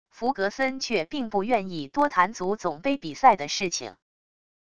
弗格森却并不愿意多谈足总杯比赛的事情wav音频生成系统WAV Audio Player